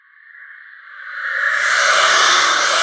ghostly_foreshadow.wav